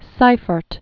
(sīfərt), Jaroslav 1901-1986.